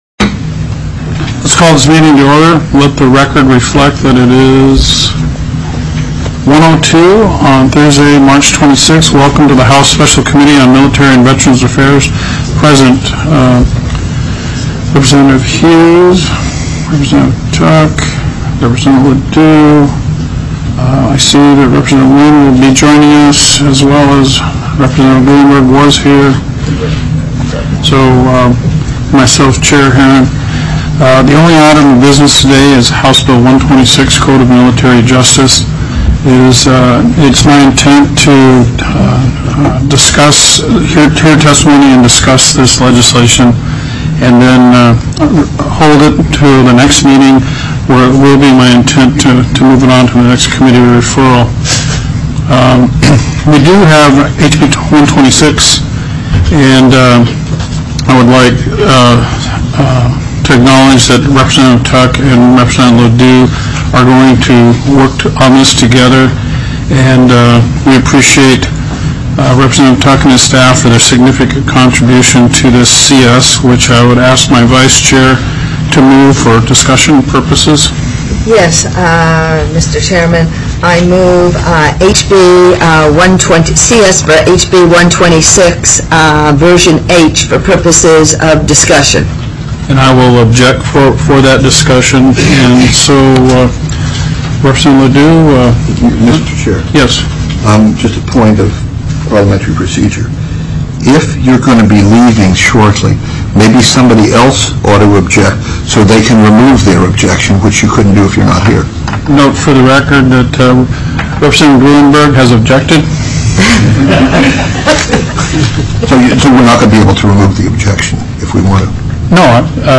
03/26/2015 01:00 PM House MILITARY & VETERANS' AFFAIRS
The audio recordings are captured by our records offices as the official record of the meeting and will have more accurate timestamps.